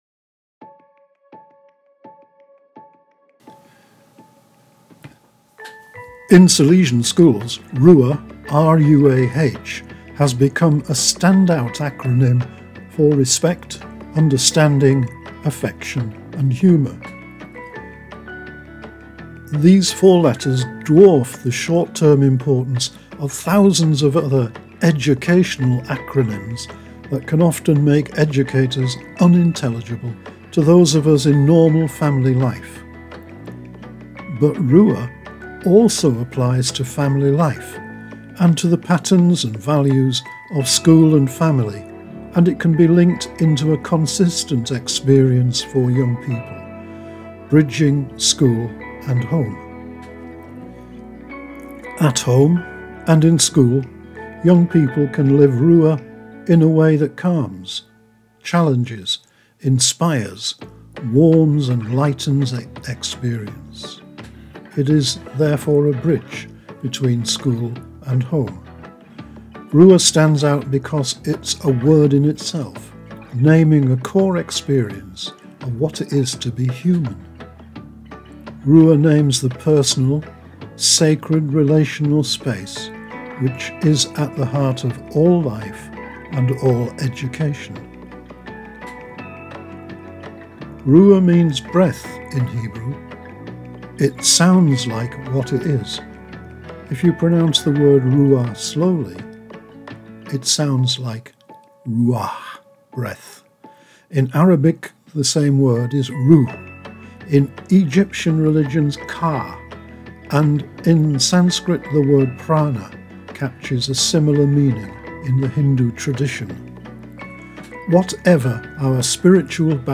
This is a self-spoken script, deliberately separated to slow the reading of the text so that it becomes a personal meditation.